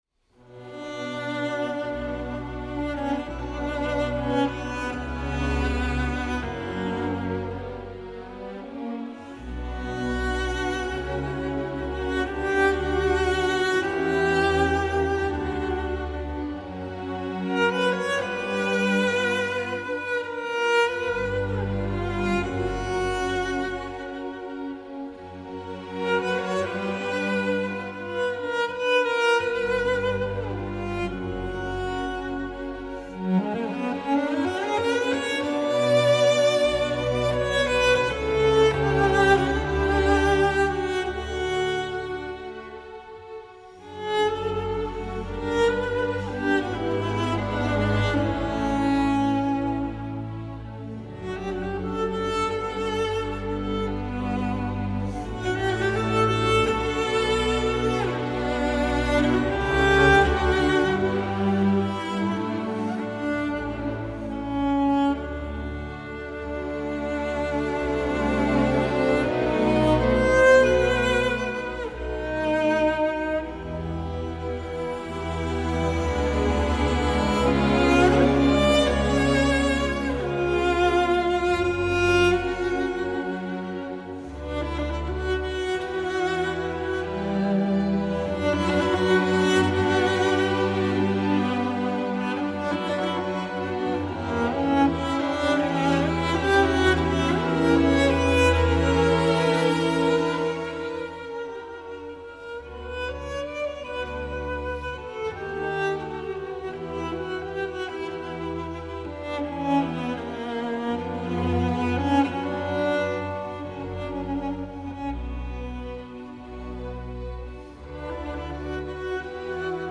se balade entre drame, mystère et mélancolie
des plages d’ambiance quelque peu translucides
un superbe thème principal porté par les cordes
un orchestre